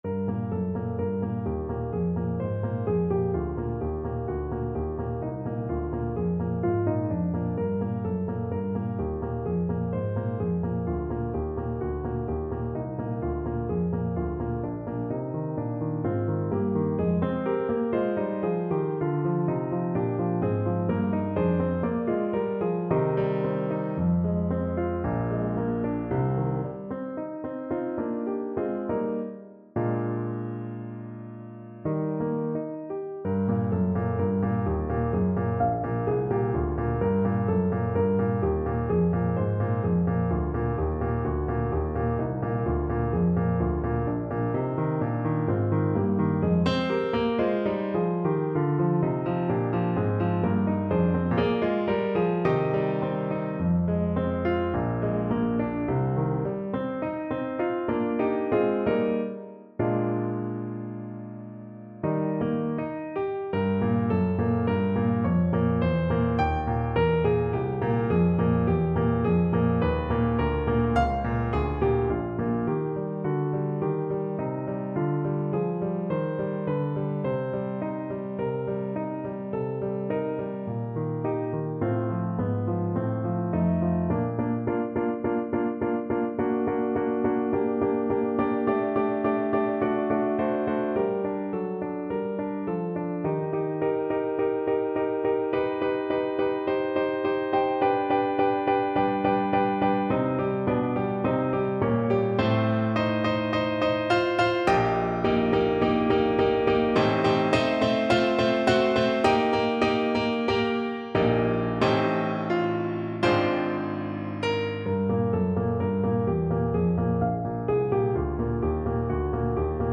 4/4 (View more 4/4 Music)
Classical (View more Classical Voice Music)